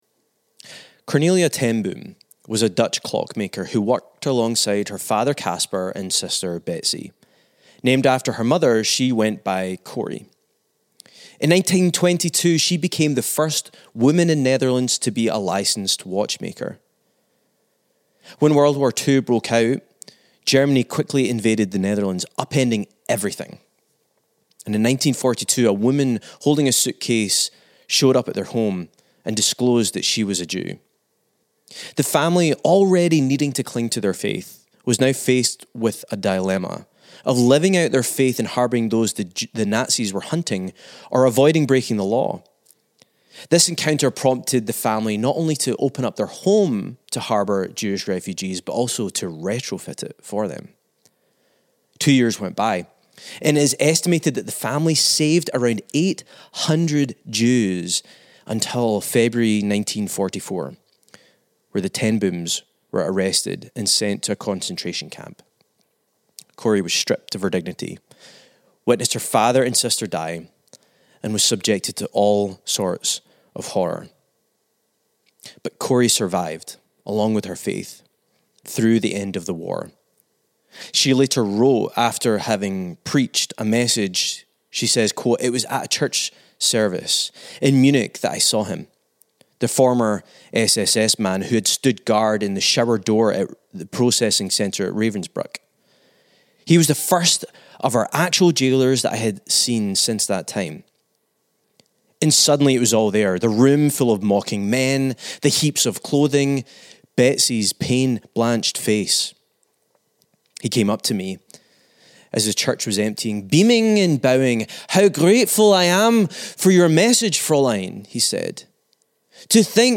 Follow along as the conversation explores what defines an enemy, how we deal with anger, and the balance of holding justice and mercy at the same time.